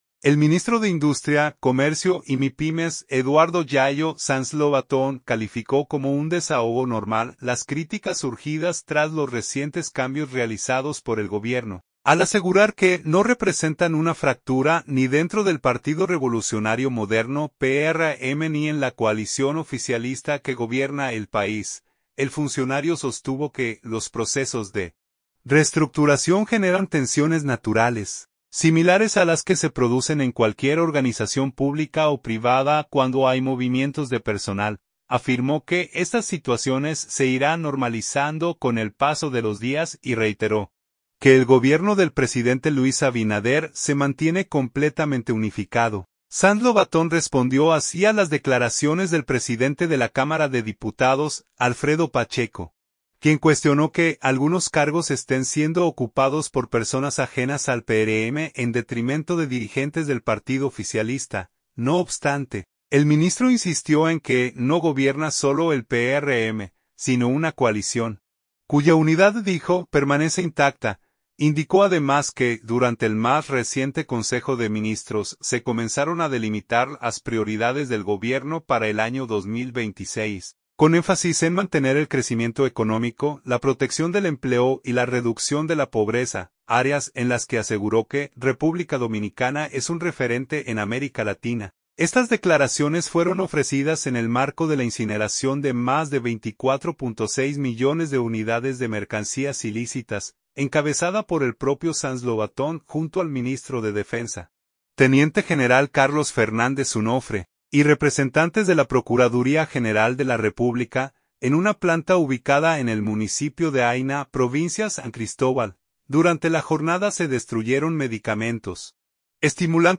Estas declaraciones fueron ofrecidas en el marco de la incineración de más de 24.6 millones de unidades de mercancías ilícitas, encabezada por el propio Sanz Lovatón junto al ministro de Defensa, teniente general Carlos Fernández Onofre, y representantes de la Procuraduría General de la República, en una planta ubicada en el municipio de Haina, provincia San Cristóbal.